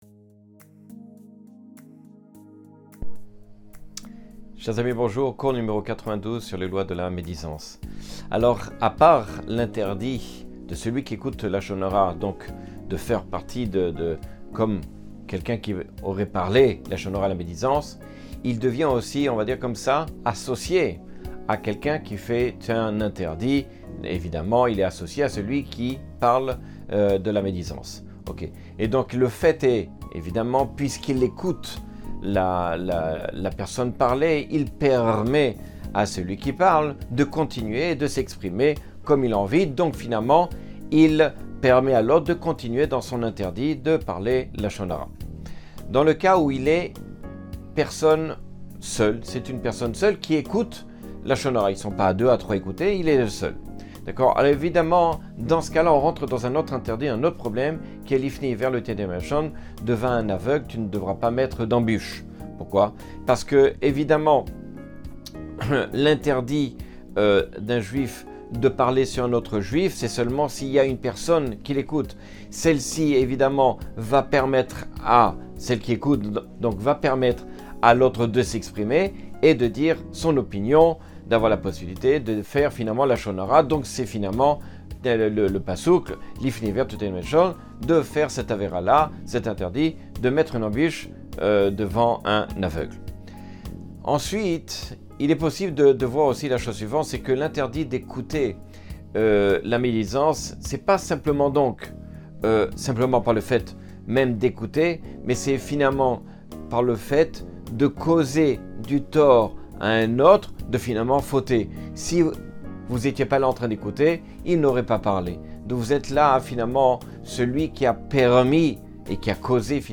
Cours 92 sur les lois du lashon hara.